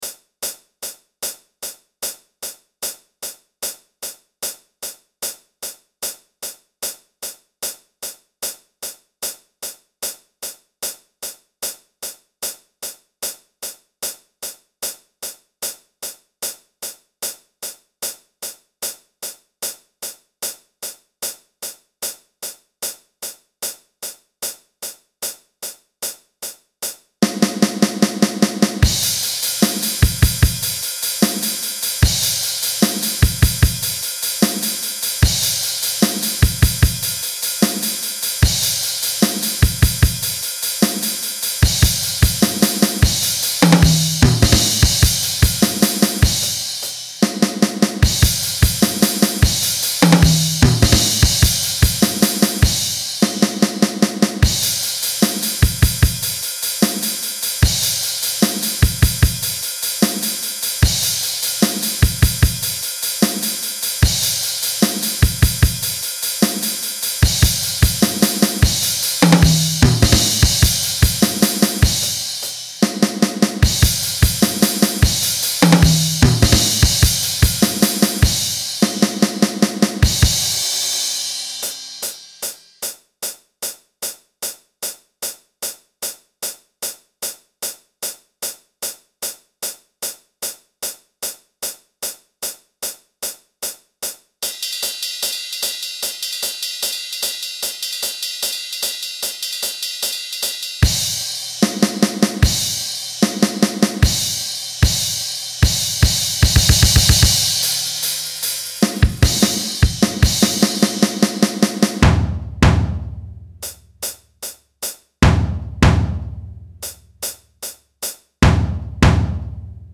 Drums only - You create what you want